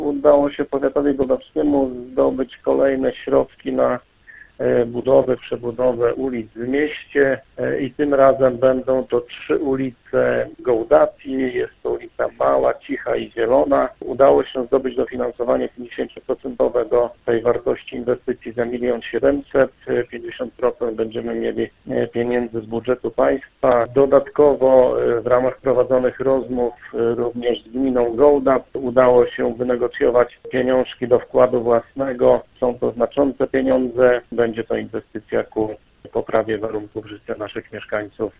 Jak mówi Radiu 5 Andrzej Ciołek, starosta gołdapski, 50% kwoty na realizację tej inwestycji udało się pozyskać z budżetu państwa.